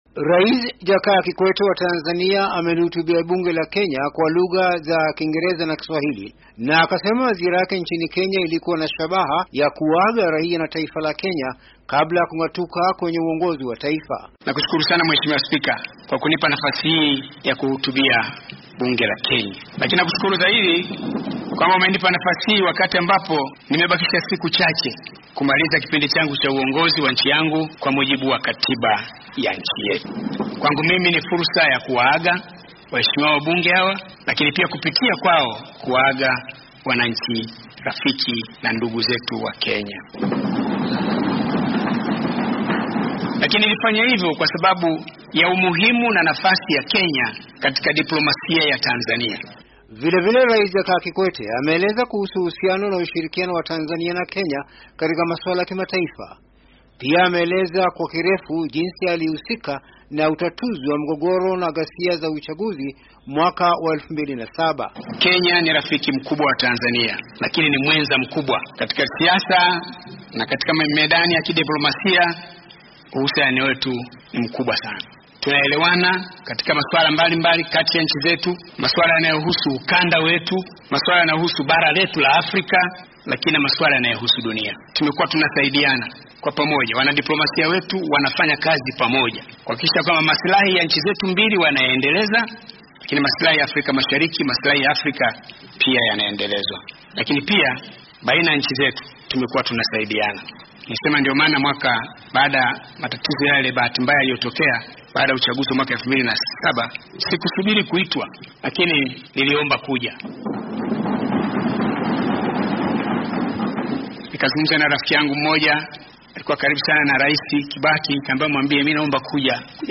Kikwete ahutubia bunge la Kenya